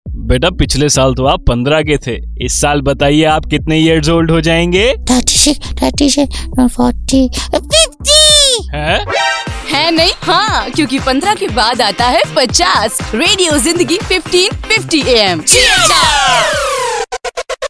This audio clip is a station bumper used in Radio Zindagi’s broadcast programming.